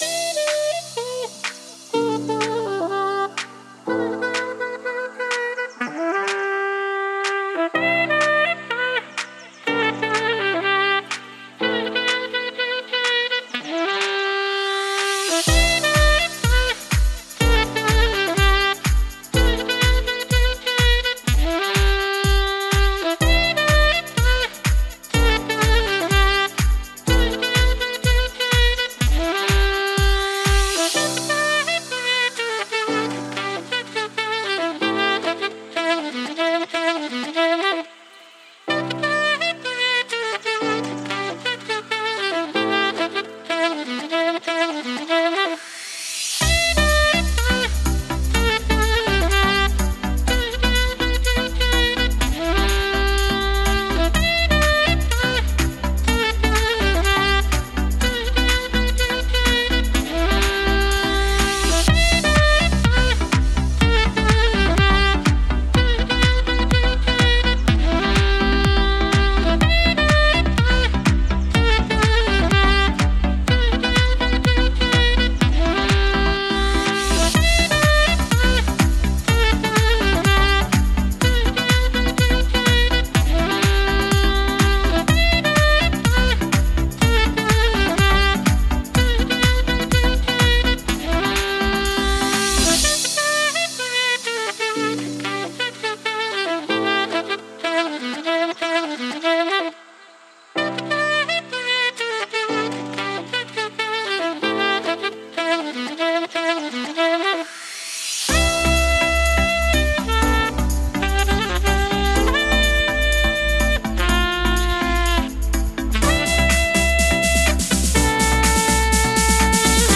پلی لیست بهترین آهنگ های ساکسوفون (بی کلام)
Saxophone